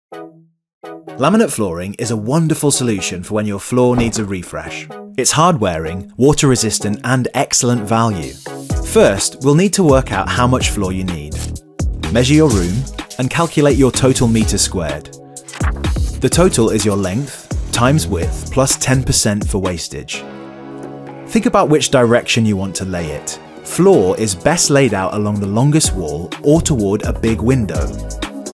Male
I work in both northern British and neutral British accents with a charming, warm, confident, trustworthy, and friendly voice.
A Narration I Did For B&Q
0409B_Q_YouTube_Instructional__Home_Studio_.mp3